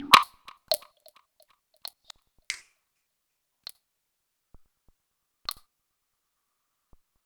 I would make a pattern using one track on the AR, then record it as a sample, then assign that sample to same track and repeat.